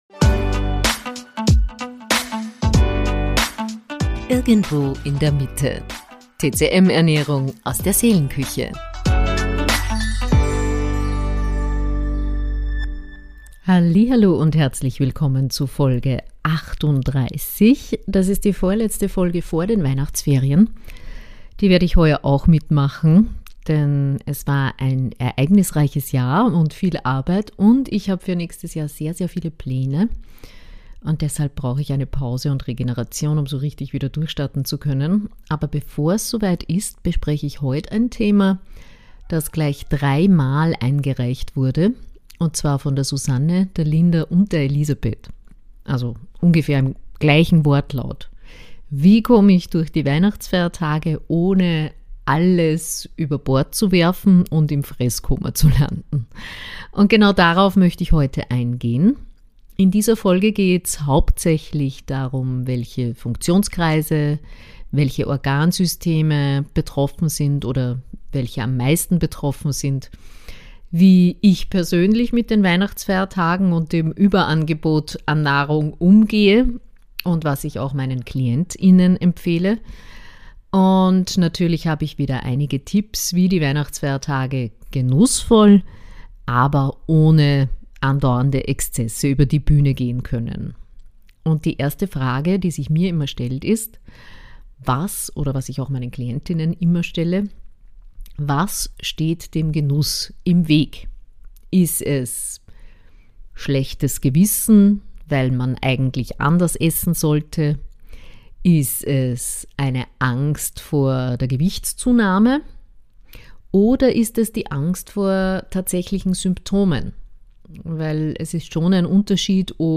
PS: Nicht wundern, im Hintergrund kräht das eine oder andere Mal mein Hahn. Er war sehr gesprächig während der Aufnahme :).